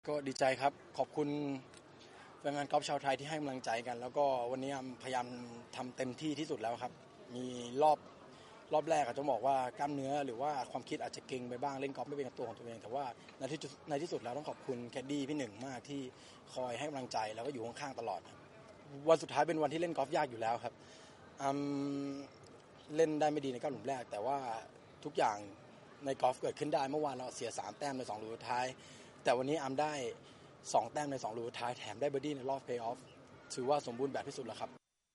ฟังคลิปเสียงโปรอาร์มหลังคว้าแชมป์ Shenzhen International